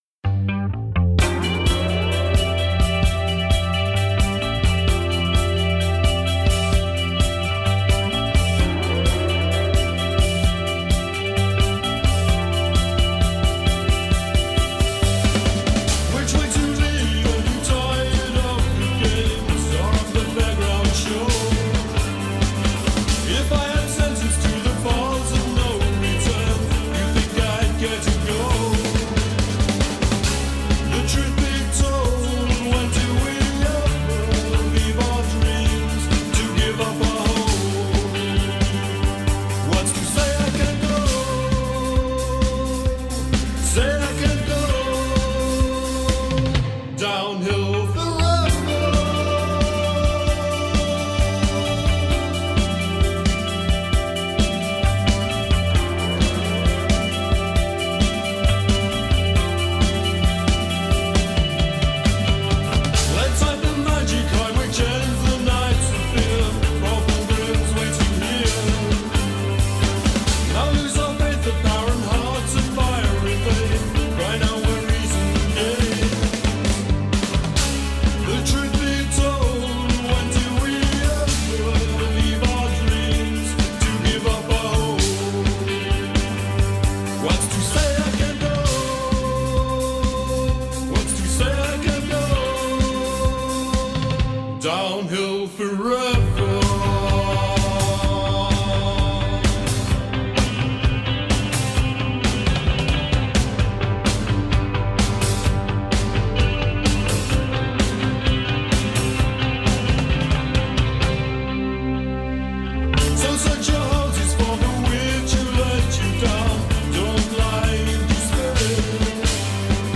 guitar/vocals
on drums
on keyboards